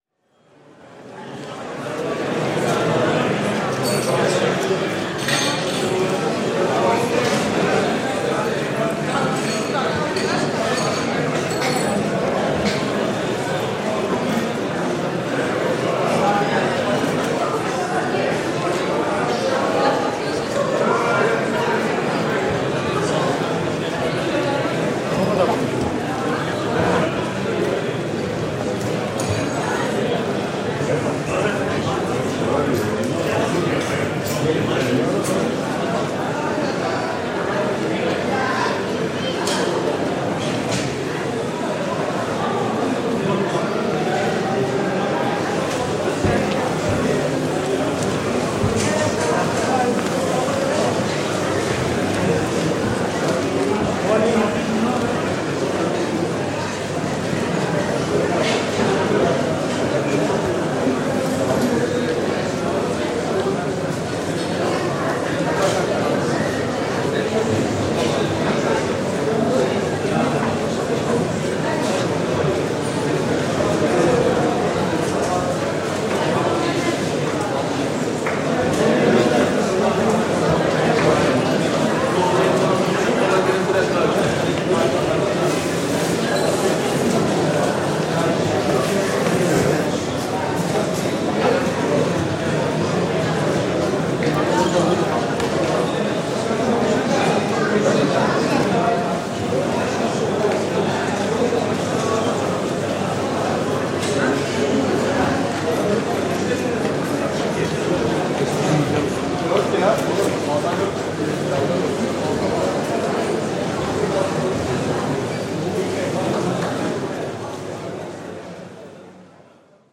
Звук большого супермаркета в Туцрии (МММ Мигрос) (02:00)